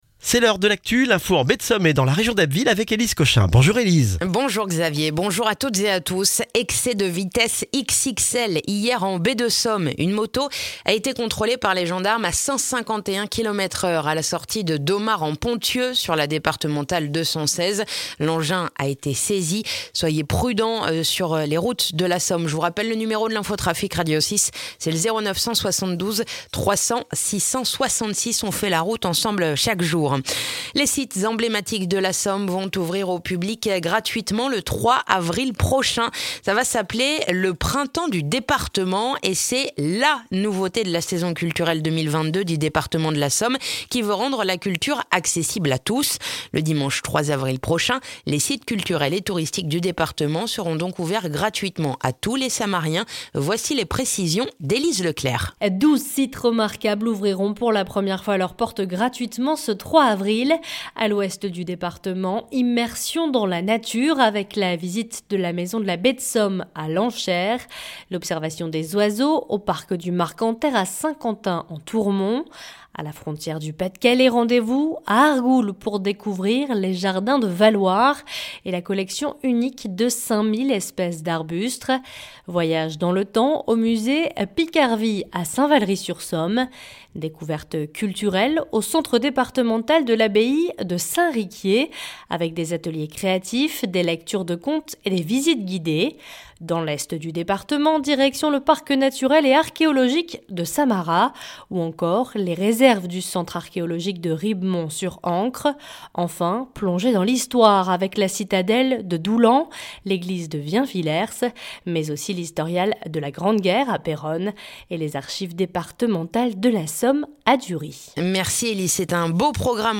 Le journal du mardi 22 mars en Baie de Somme et dans la région d'Abbeville